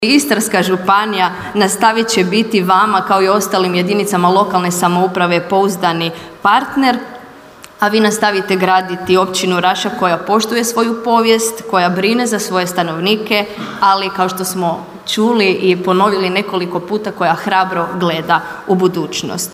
Svečanom sjednicom Općinskog vijeća Općina Raša jučer je proslavila svoj dan.